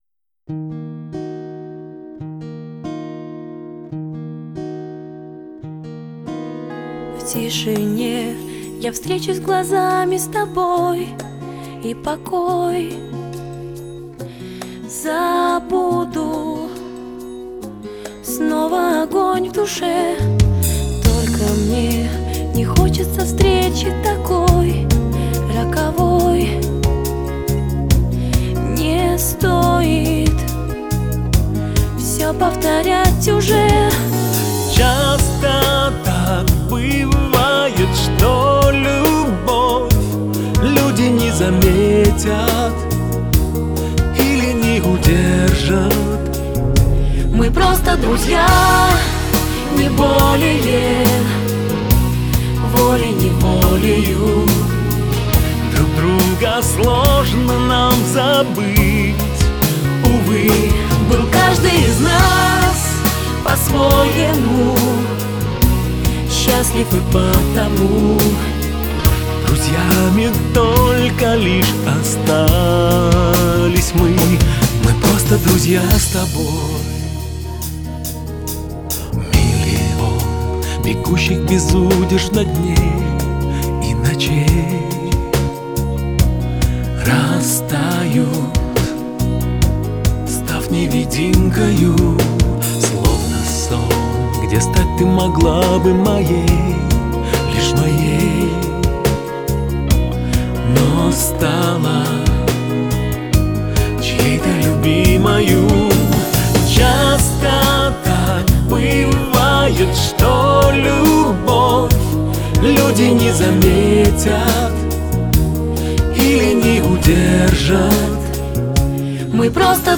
это яркая поп-песня